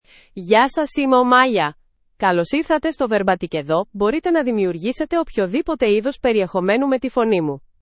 MayaFemale Greek AI voice
Maya is a female AI voice for Greek (Greece).
Voice sample
Female
Maya delivers clear pronunciation with authentic Greece Greek intonation, making your content sound professionally produced.